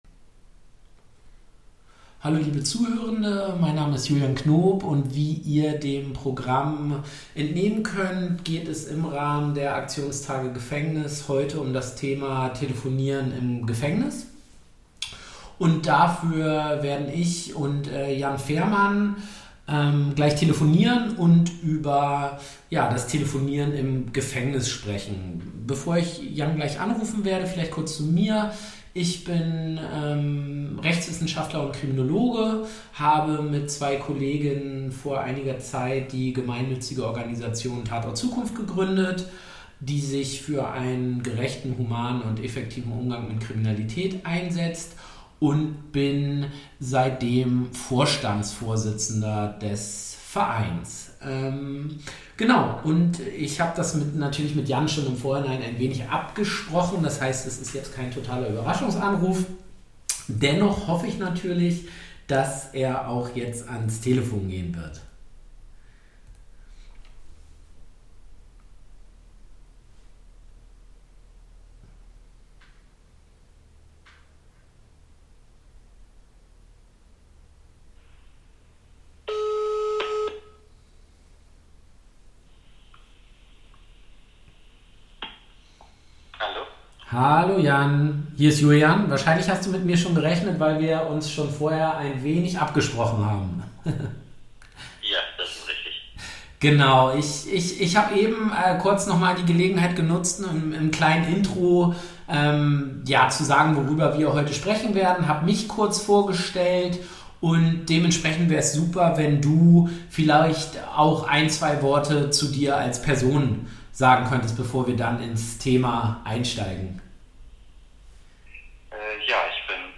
telefonat.mp3